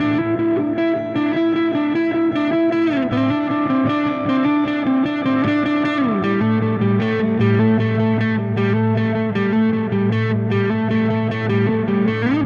Spaced Out Knoll Electric Guitar 03c.wav